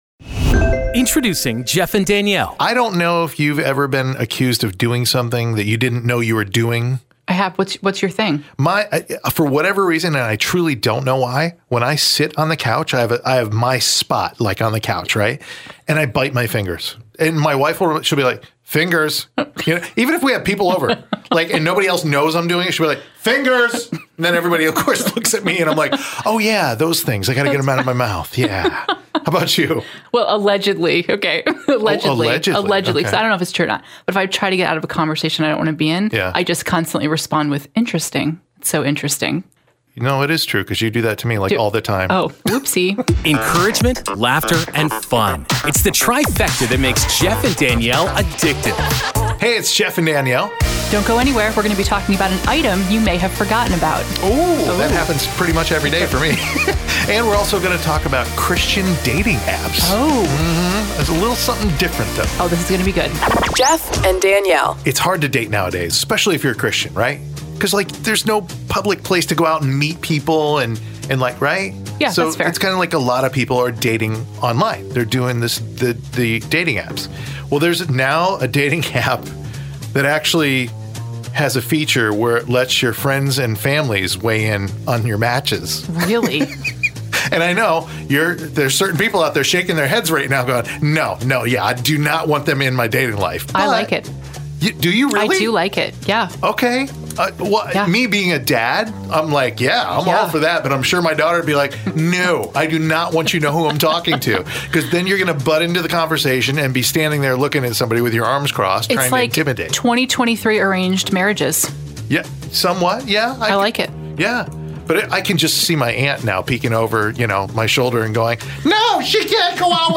CCM Format
Unlike other programs, they seamlessly intertwine uplifting music with heartfelt discussions and topics, fostering a sense of community and spiritual fellowship.
Contemporary Christian music Morning Show